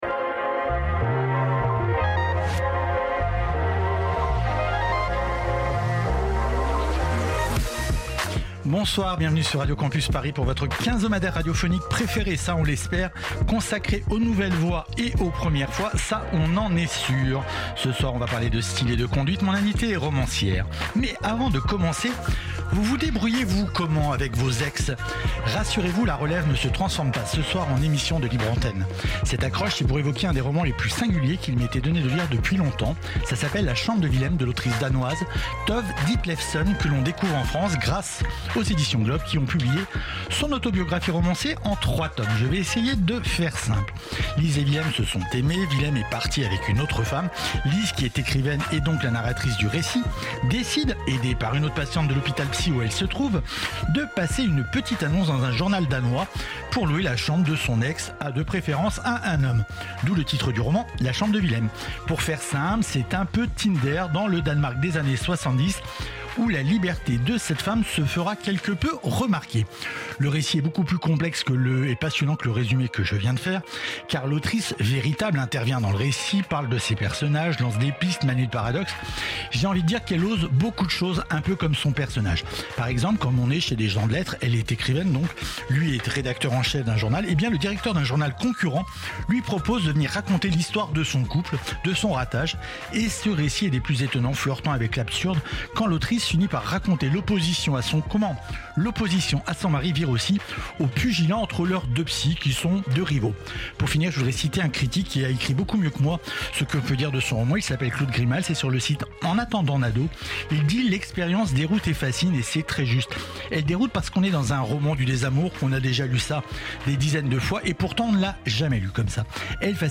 Type Entretien Culture